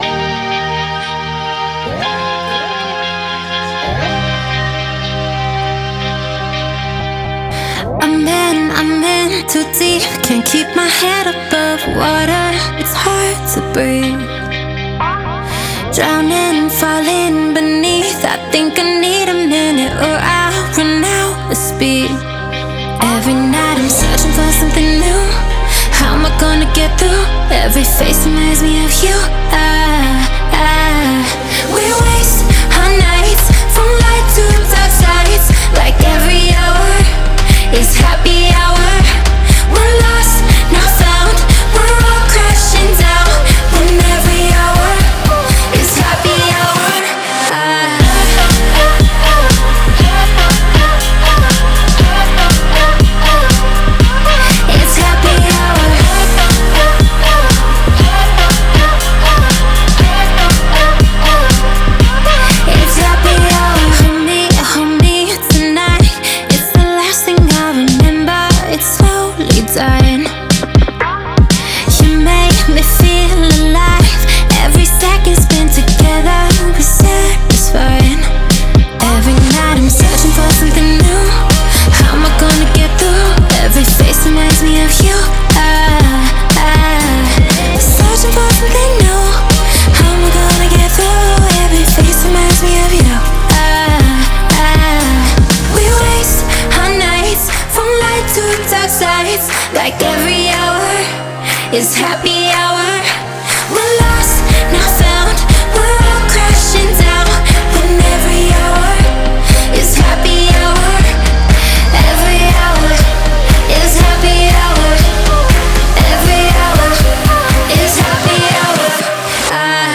энергичная электронная композиция
Звучание отличается яркими синтезаторами